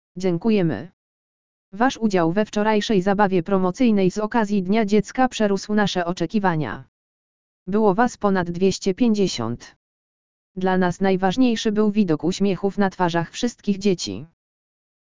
lektor_audio_dziekujemy.mp3